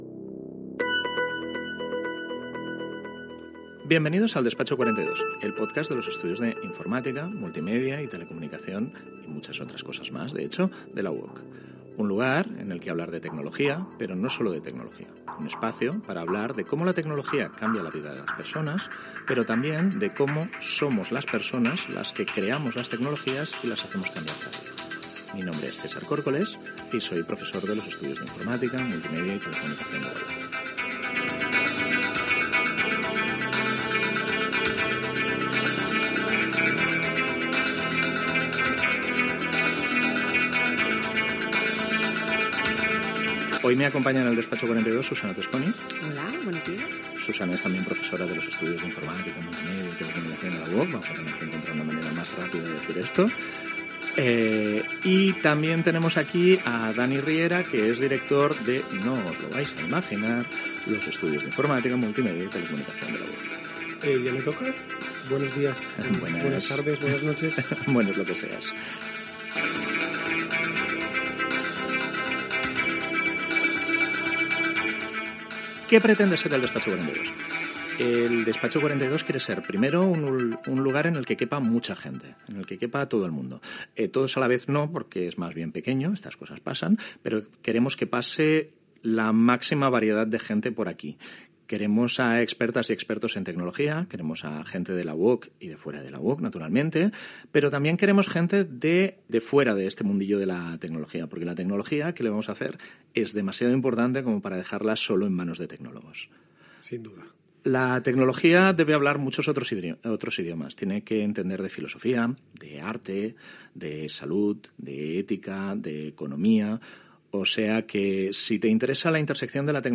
Presentació del primer programa, equip i objectiu. L'època postdigital Gènere radiofònic Divulgació